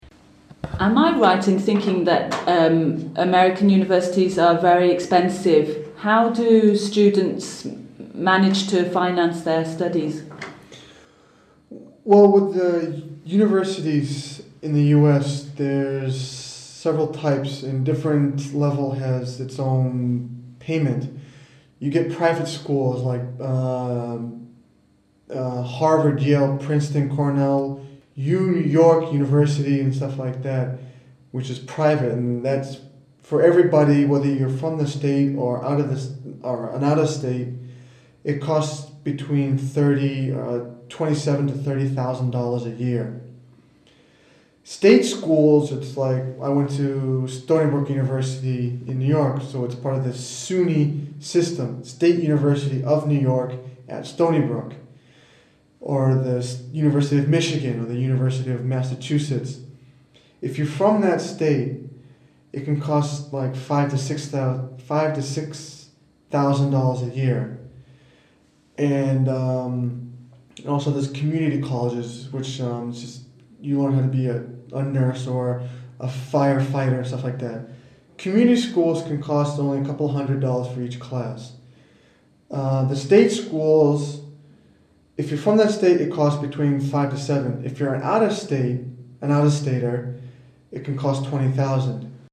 Britannique / Américain